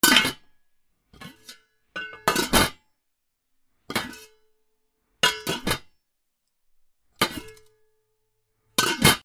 なべ ふたの開け閉め
『カラン』